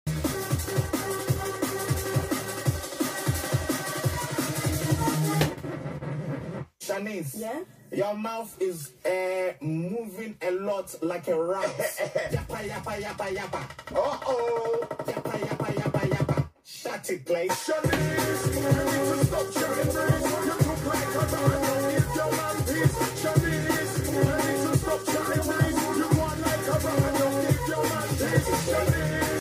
DnB live DJ set tonight sound effects free download